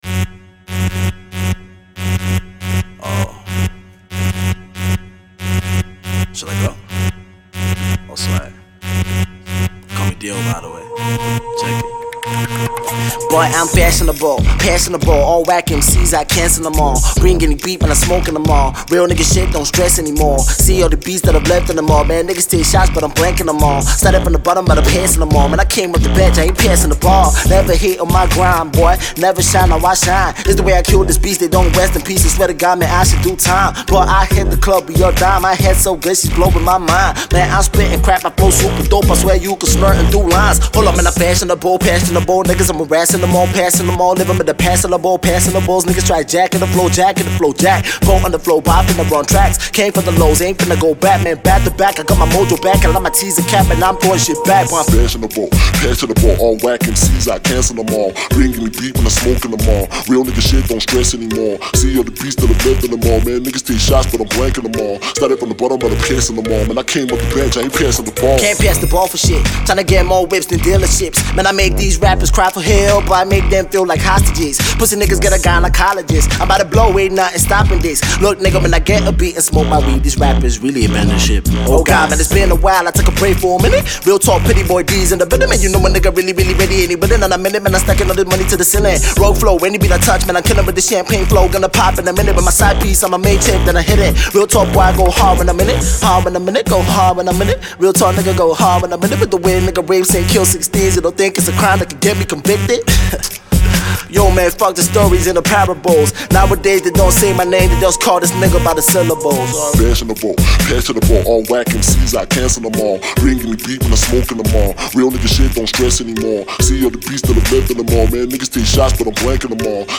Hefty beats and a fluent flow